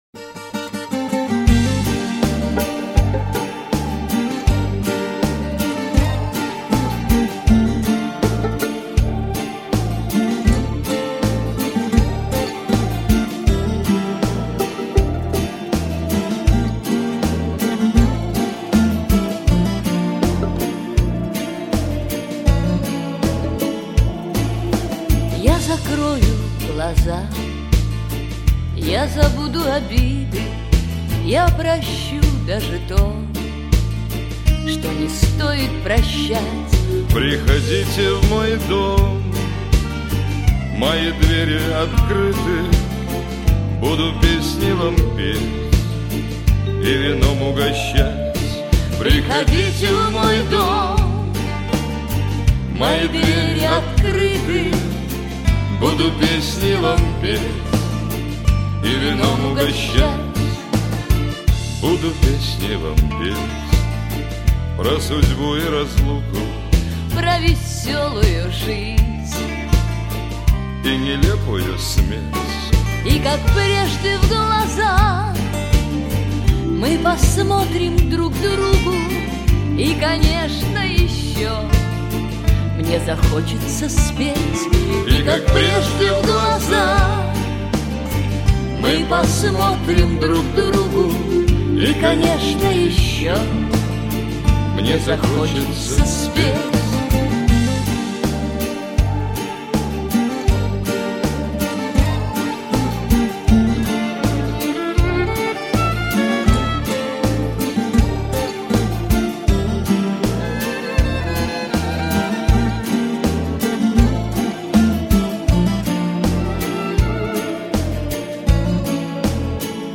您当前位置：网站首页 > 香颂（шансон）界